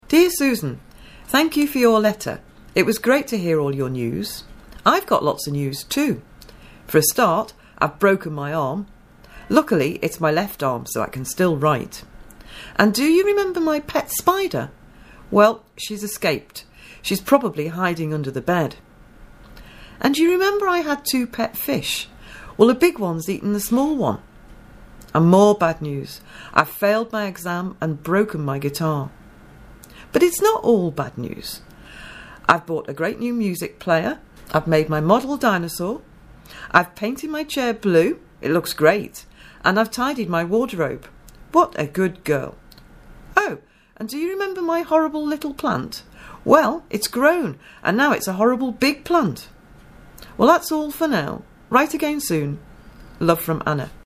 Whats New audio letter.mp3